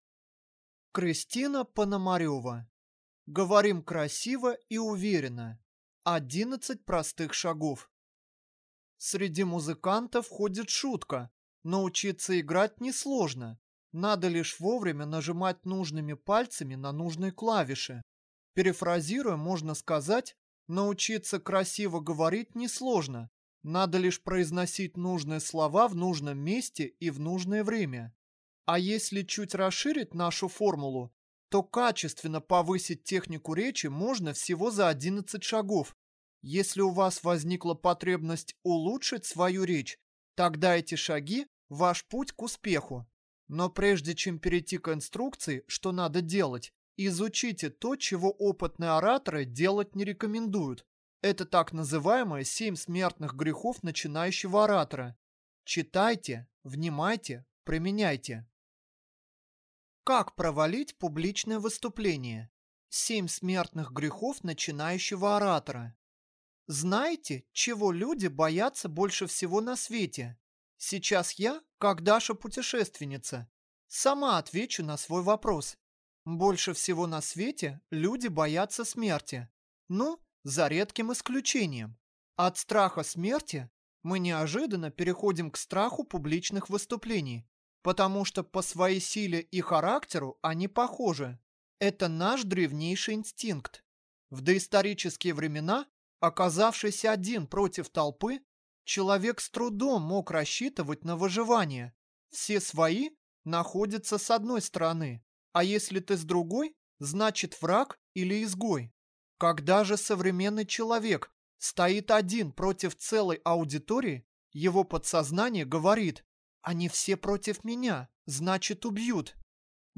Аудиокнига Говорим красиво и уверенно. 11 простых шагов | Библиотека аудиокниг